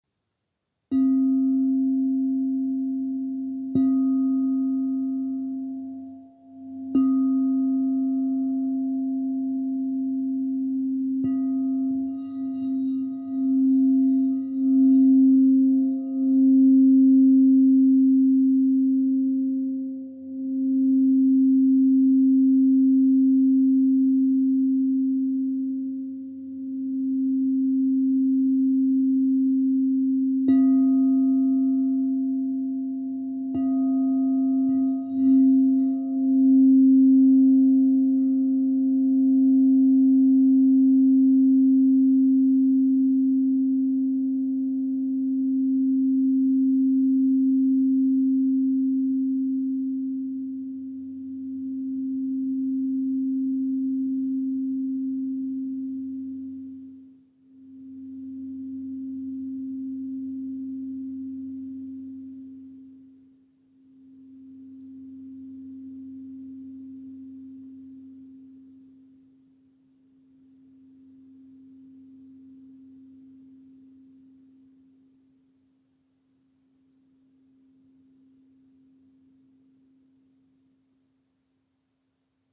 "Zpívající" Křišťálové mísy
Mísa tón C velikost 9" (23cm)
Ukázka mísa C
Mísa C.m4a